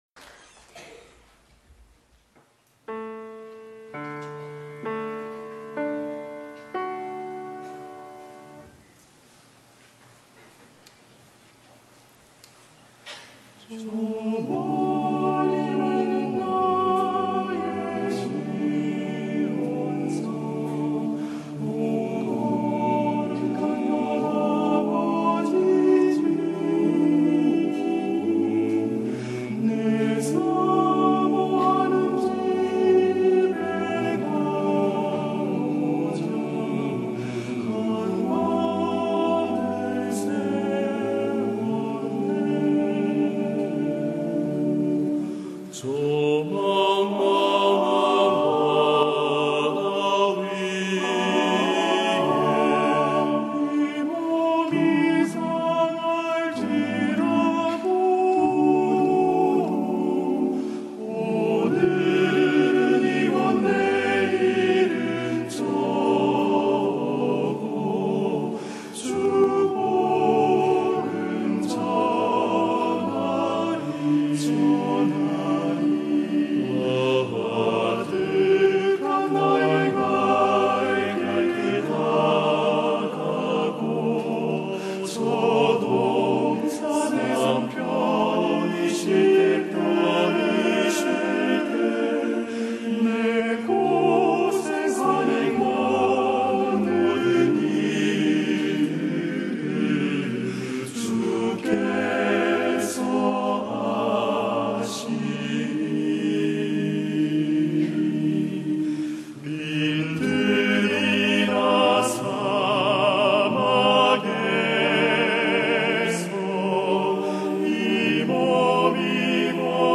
GoodNewsTV Program 성가공연 저 멀리 뵈는 나의 시온성